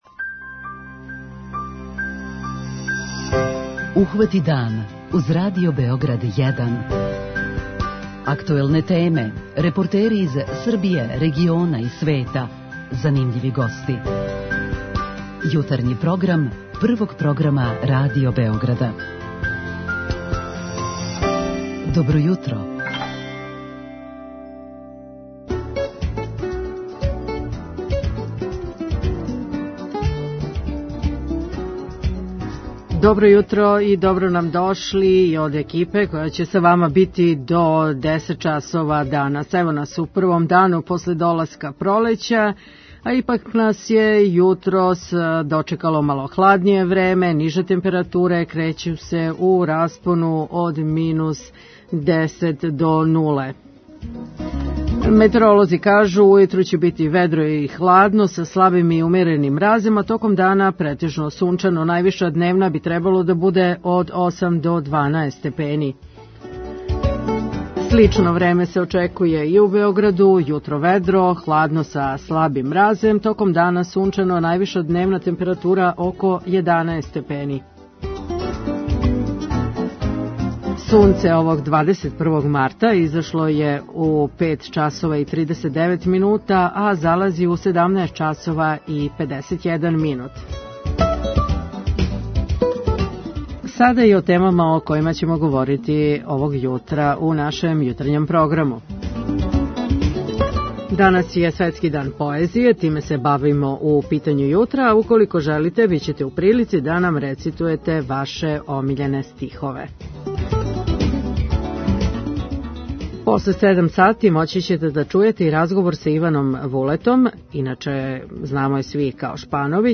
У нашем питању јутра имаћете прилику да нам кажете ваше мишљење да ли је поезија успела да се избори за своје место у савременом начину живота и уколико имате жељу да нам изрецитује стихове који су вам остали у најлепшем сећању.
Јутарњи програм Радио Београда 1!